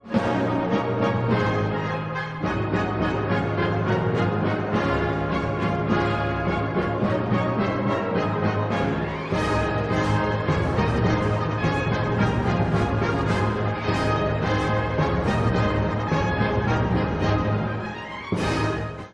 古い音源なので聴きづらいかもしれません！（以下同様）
明暗をとりまぜながら、他にも、スラヴ風の主題が次々と出現します。
曲はやがて、戦争の不安激しい戦意を描き出していきます。